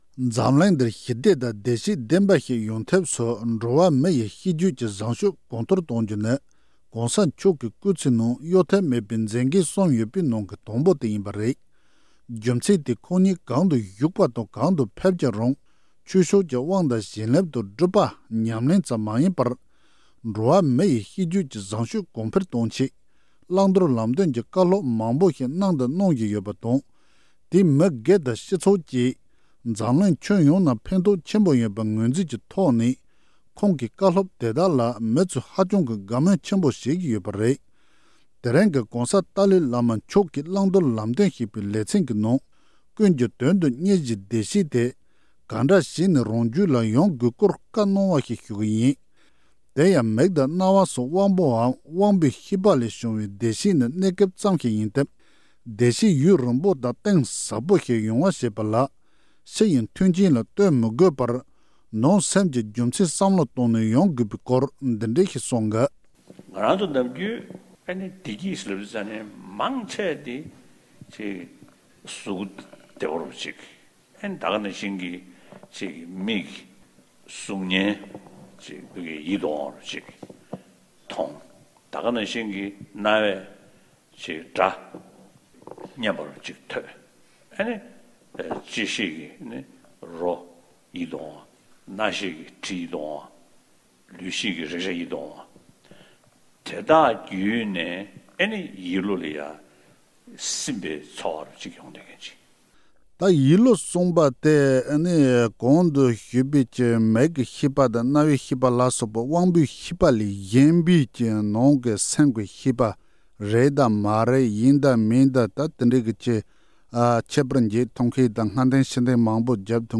༸གོང་ས་མཆོག་གིས་ཀུན་གྱི་དོན་དུ་གཉེར་བྱའི་བདེ་སྐྱིད་དེ་གང་འདྲ་བྱས་ནས་ཡོང་དགོས་པའི་སྐོར་ལ་བཀའ་སློབ།